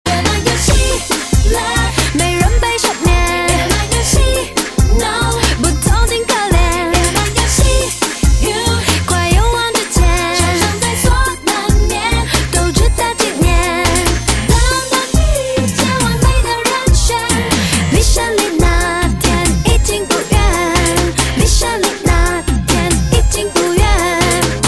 M4R铃声, MP3铃声, 华语歌曲 34 首发日期：2018-05-15 22:44 星期二